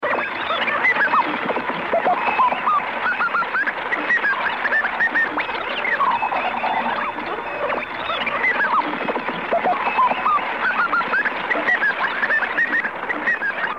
Radio noise